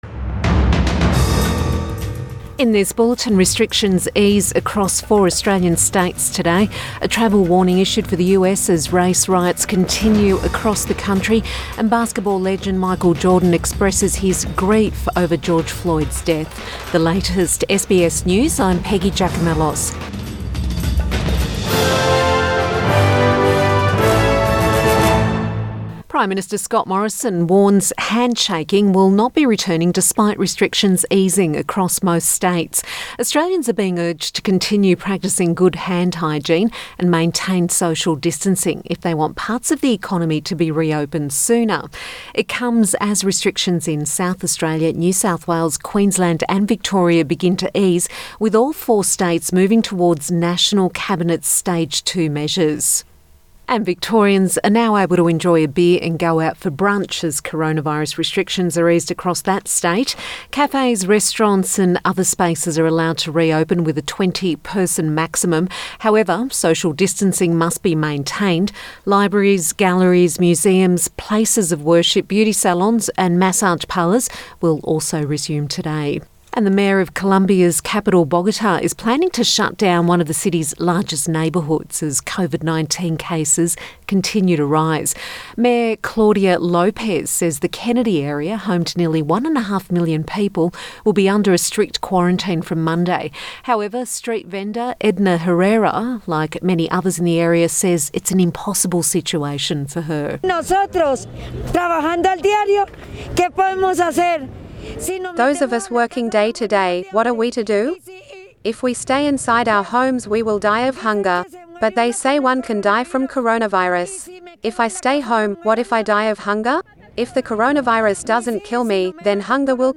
Midday bulletin June 1 2020